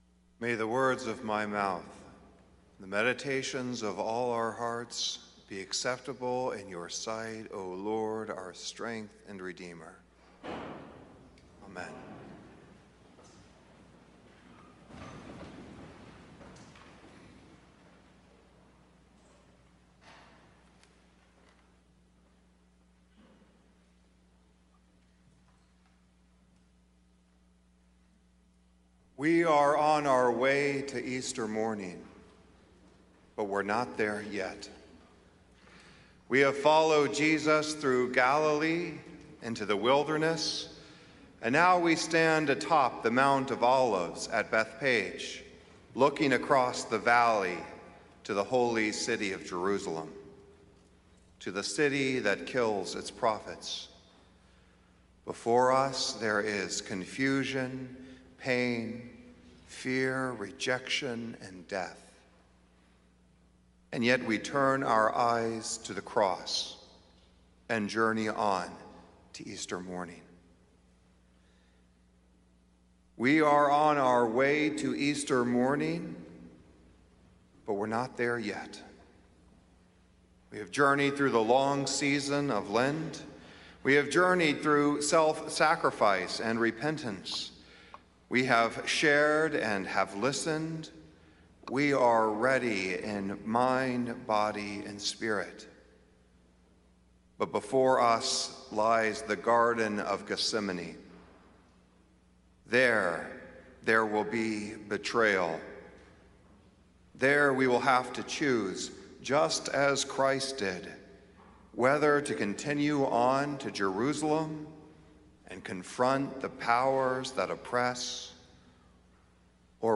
Sermons from St. Columba's in Washington, D.C.